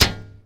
shield-hit-12.ogg